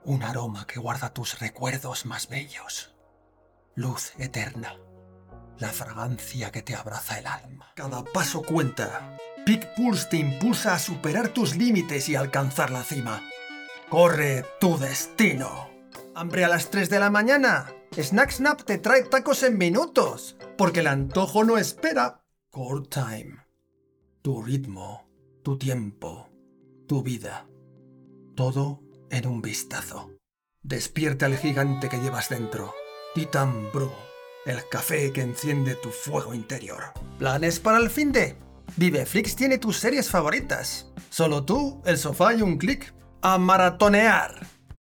HOMBRES (de 35 a 50 años)